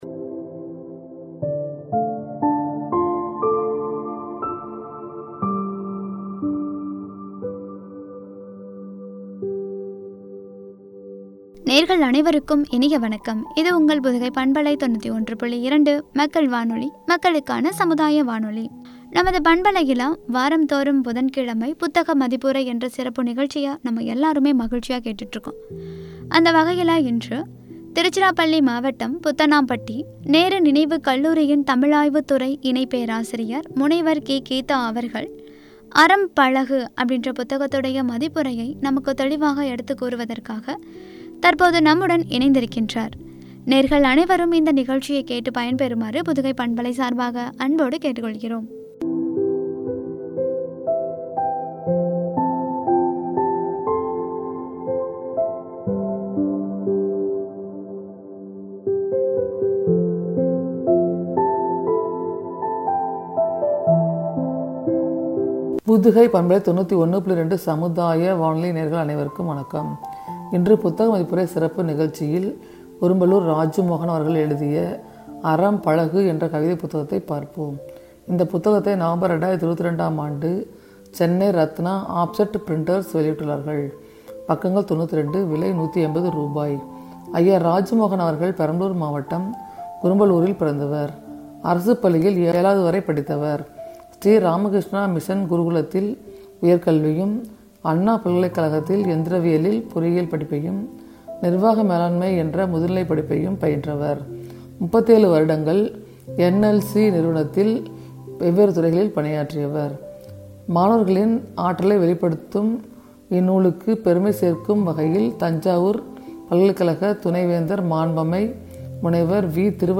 “அறம் பழகு” (புத்தக மதிப்புரை பகுதி 136) என்ற தலைப்பில் வழங்கிய உரை.